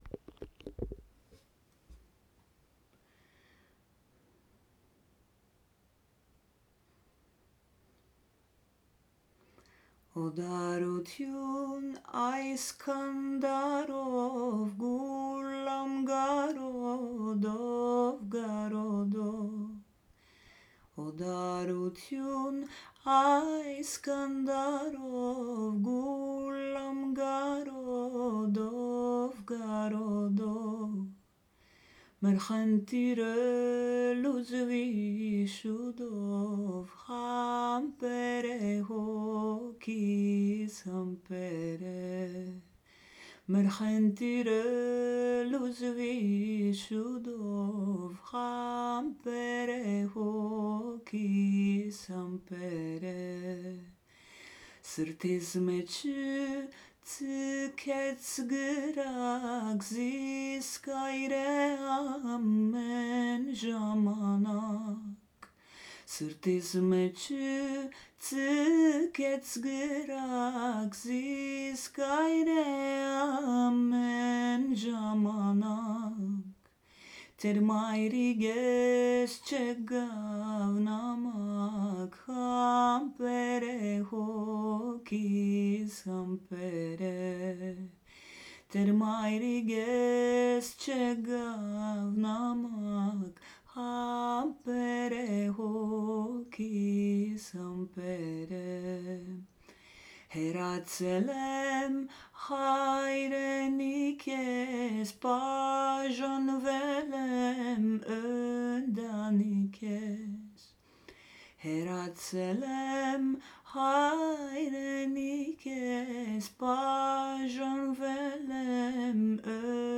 Armenian folk song